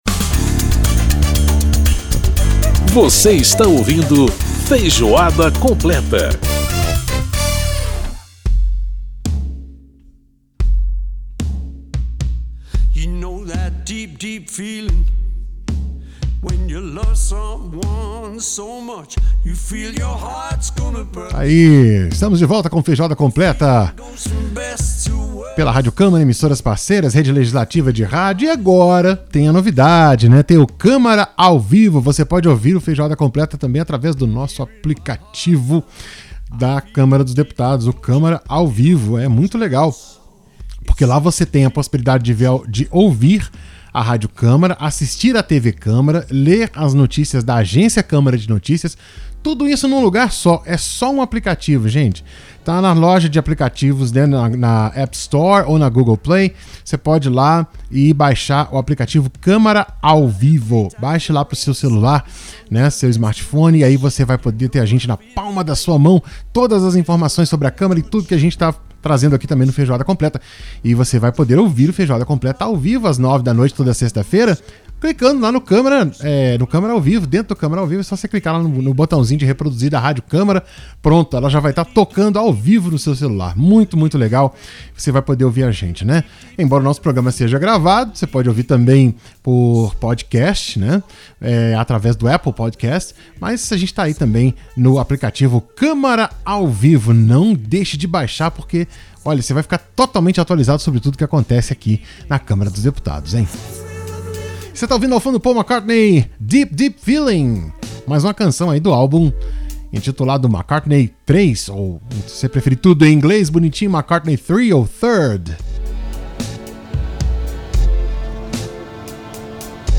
Auxílio emergencial: entrevistas - bloco 2 - Rádio Câmara